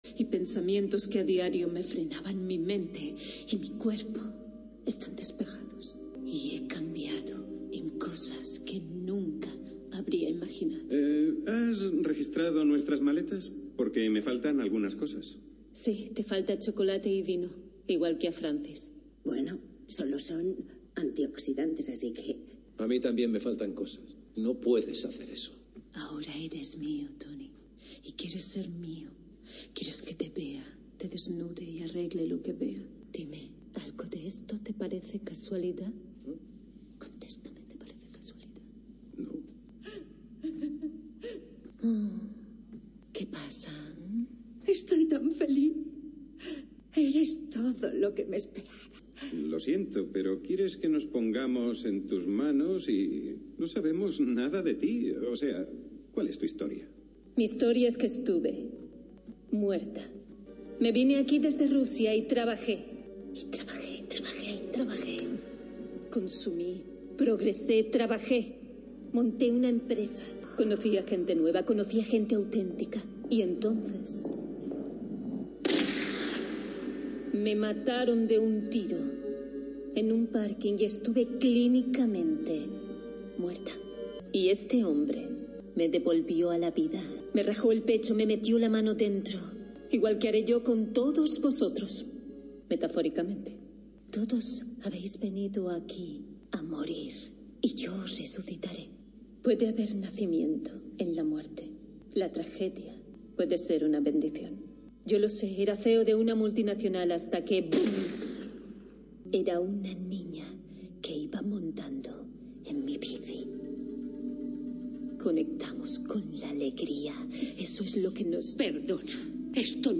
Magazín local d'entreteniment